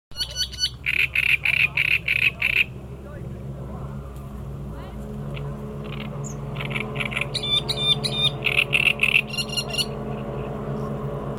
дроздовидная камышевка, Acrocephalus arundinaceus
Ziņotāja saglabāts vietas nosaukumsLīdumu karjers
Skaits3 - 4
СтатусПоёт